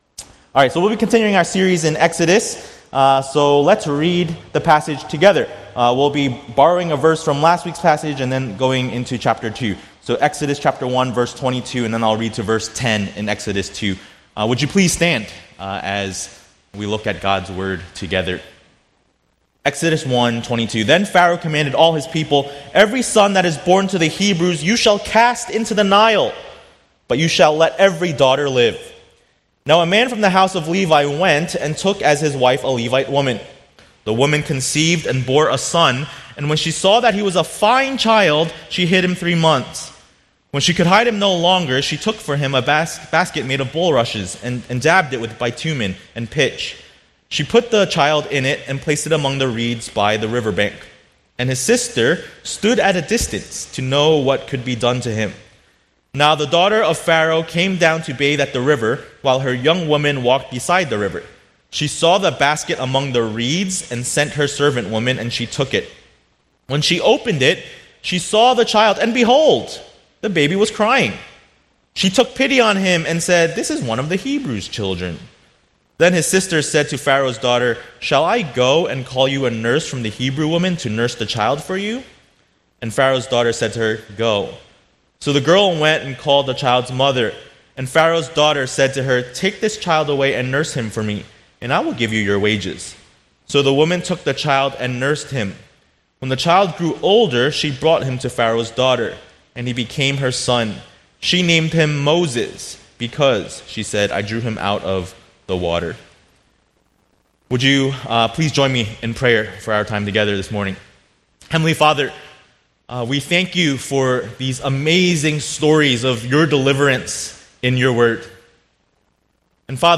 A message from the series "Sojourners: Exodus ."